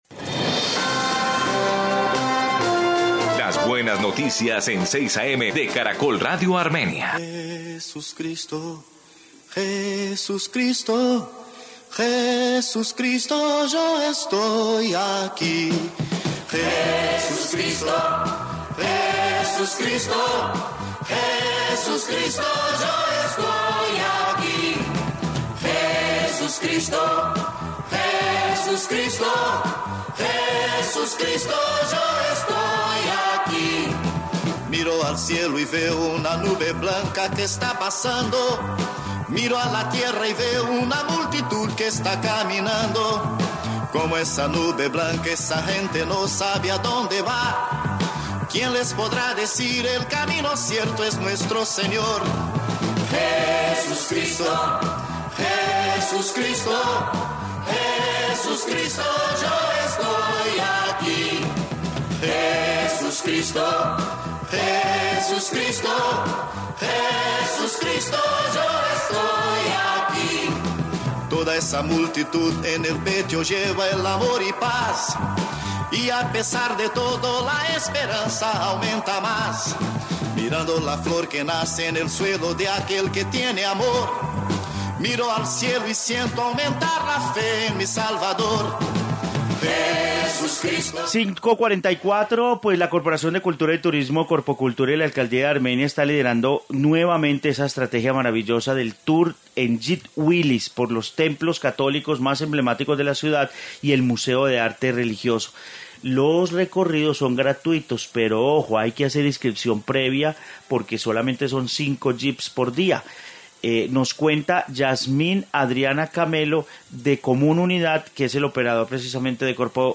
Informe Jeep Tour en Semana Santa